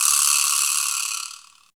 87 VIBRASL-L.wav